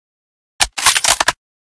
bolt.ogg